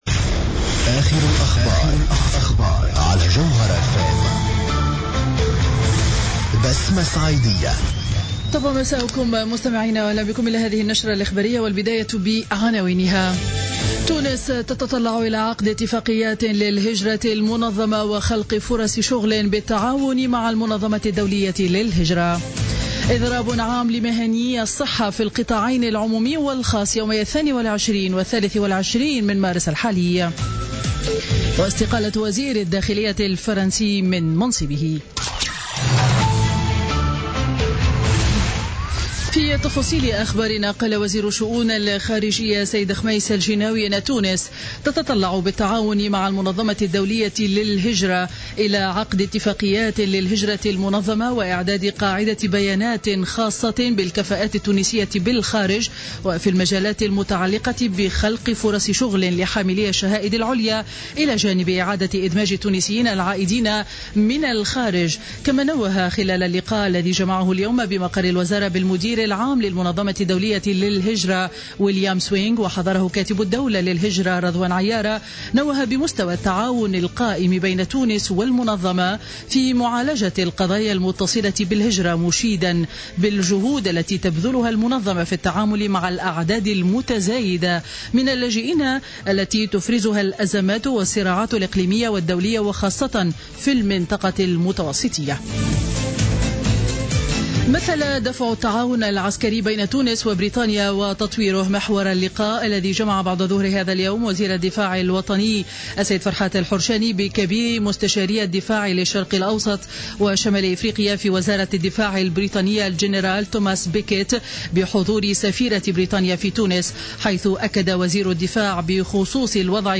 نشرة أخبار السابعة مساء ليوم الثلاثاء 21 مارس 2017